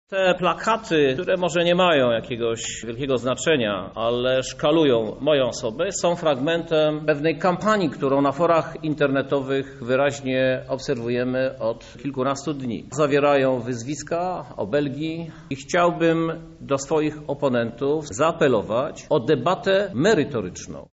– Nie brakuje możliwości prowadzenia ze mną debaty merytorycznej – mówi prezydent Krzysztof Żuk